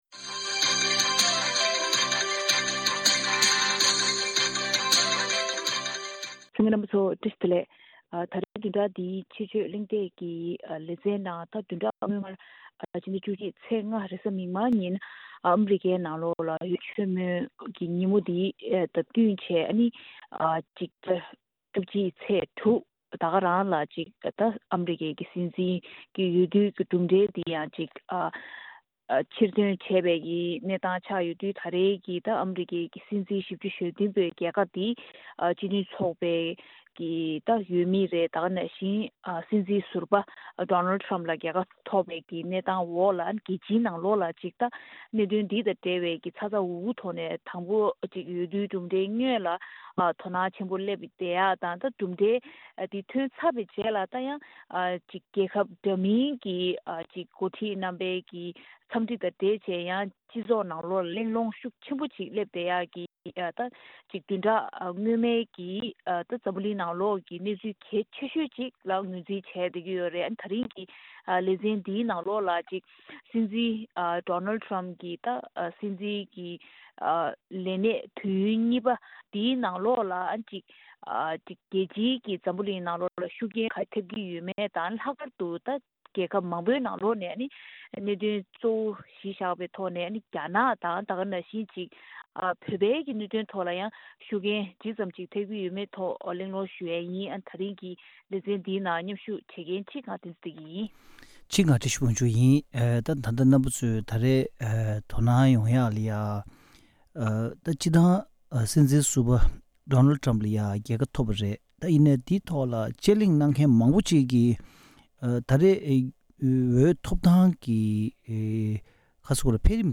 དཔྱད་བརྗོད་གླེང་སྟེགས